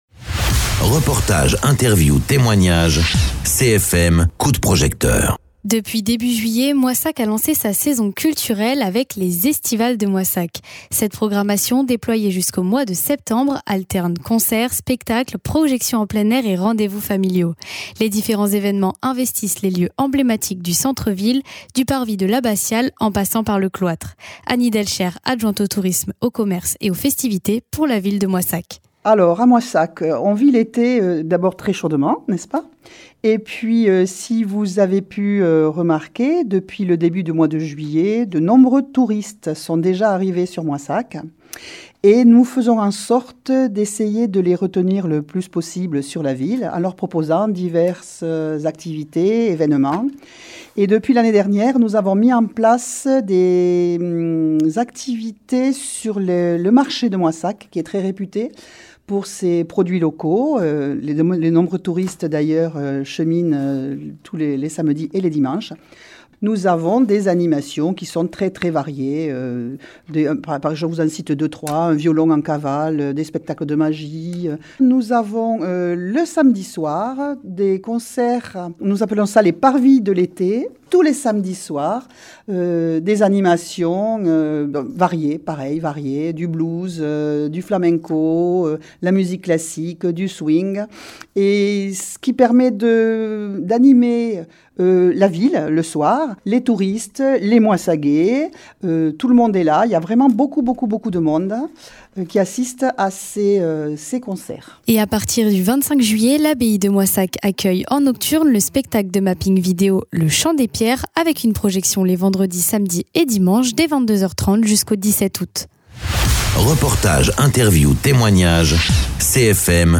Interviews
Invité(s) : Any Delcher Adjointe au tourisme commerce et aux festivités pour la ville de Moissac